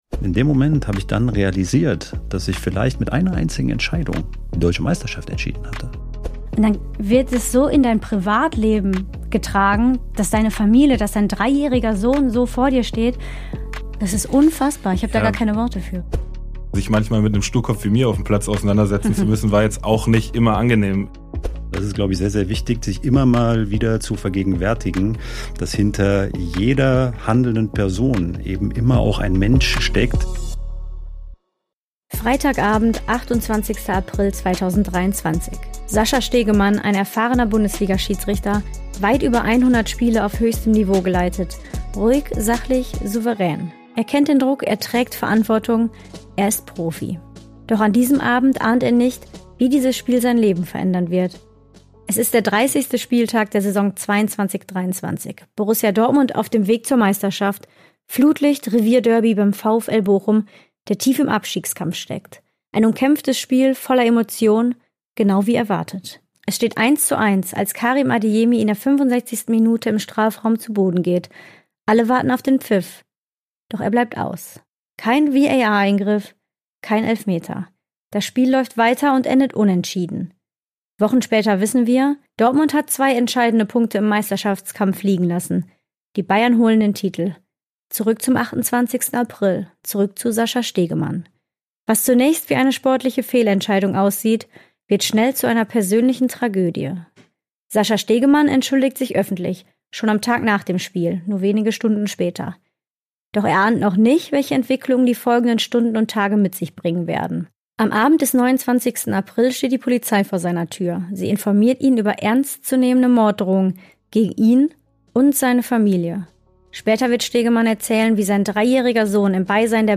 Studio & Postproduktion: Foundation Room Studio